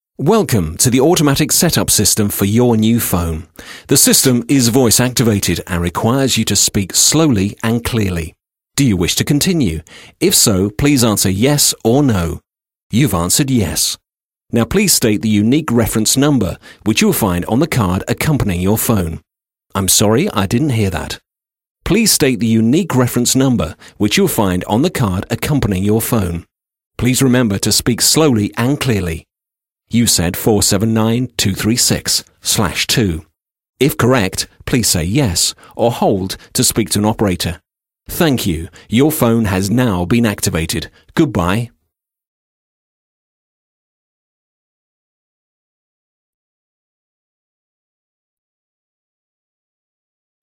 With twenty years of experience working in radio for the BBC and a rich, warm, cultured voice
03-Phone-Set-Up_.mp3